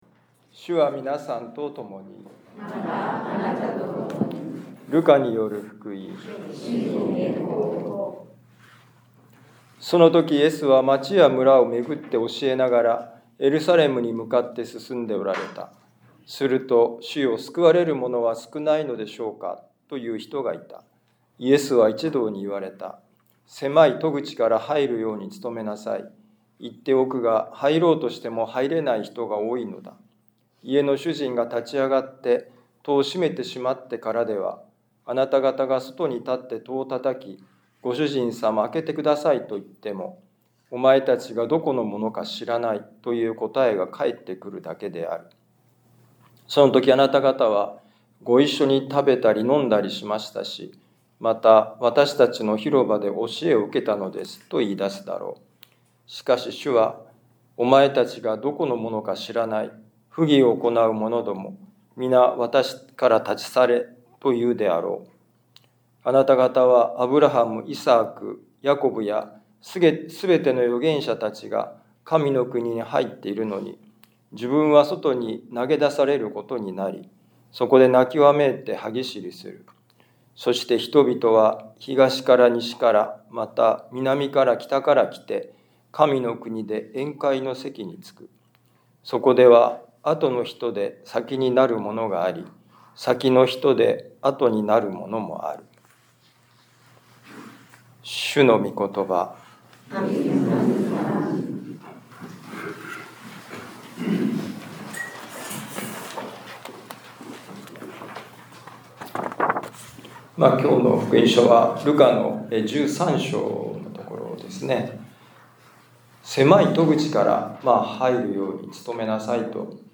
ミサ説教
ルカ福音書13章22-30節「人生の狭い戸口」2025年8月24日年間第21主日ミサカトリック彦島教会